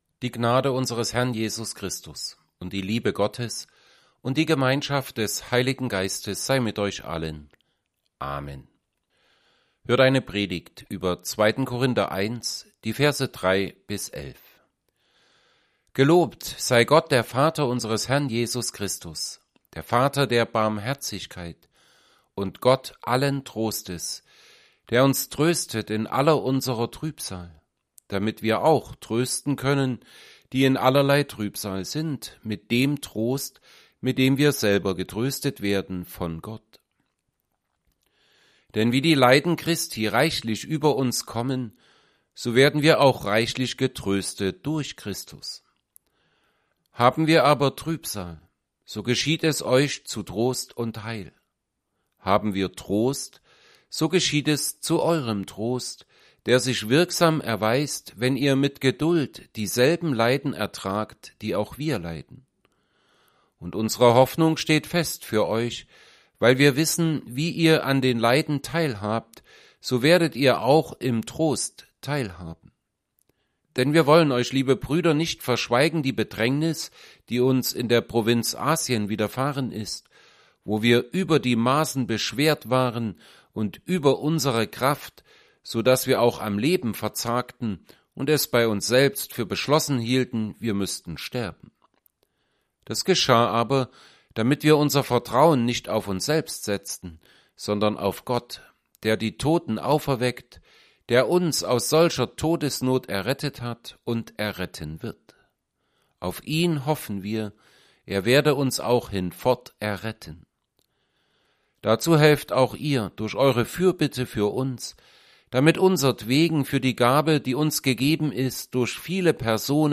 Predigt_zu_2Korinther_1_3b11.mp3